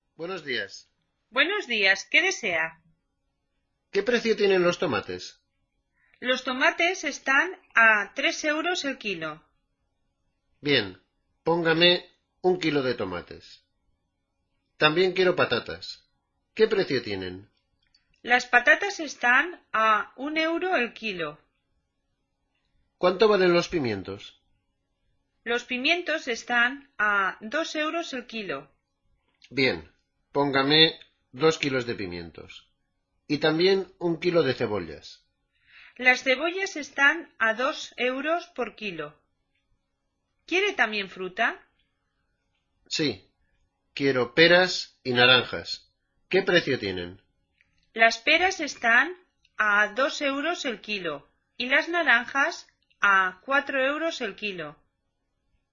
Escucha el siguiente diálogo en un mercado y anota el precio de los productos que escuches (los oirás en distinto orden).